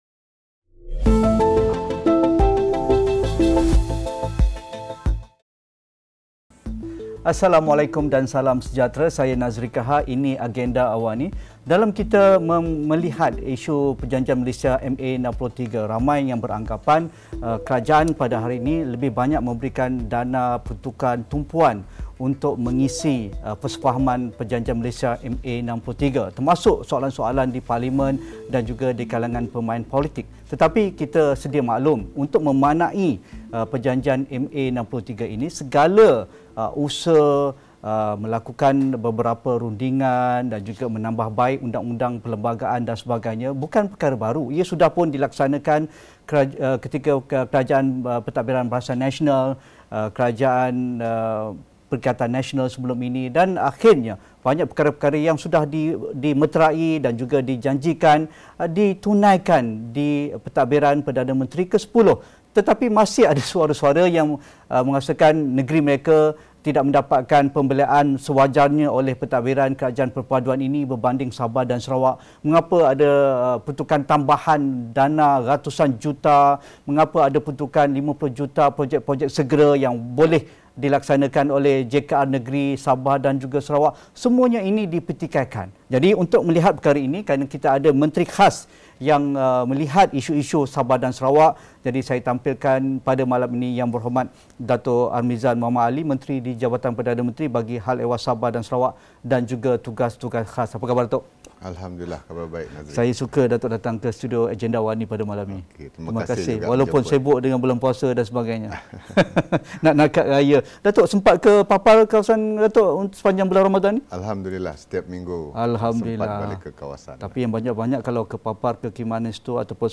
Temu bual khas bersama Menteri di Jabatan Perdana Menteri (Hal Ehwal Sabah, Sarawak dan Tugas-Tugas Khas) Datuk Armizan Mohd Ali dalam Agenda Awani 9 malam ini.